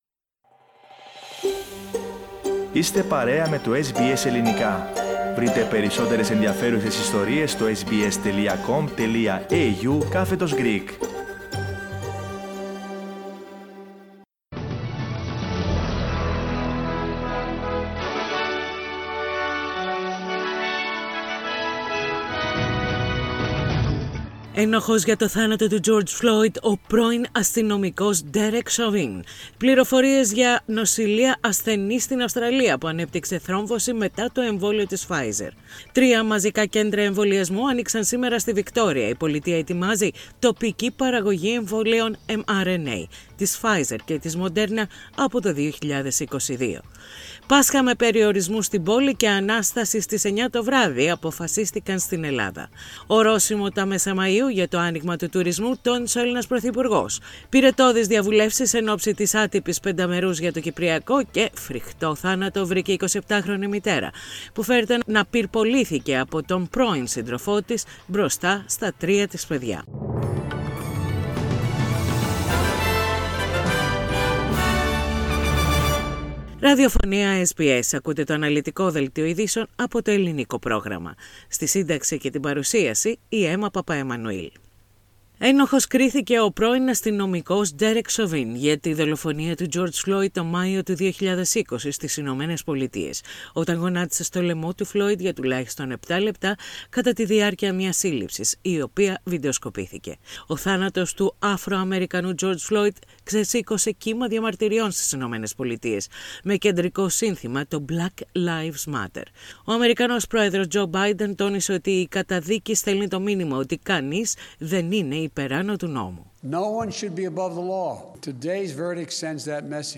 Ειδήσεις στα Ελληνικά - Τετάρτη 21.4.21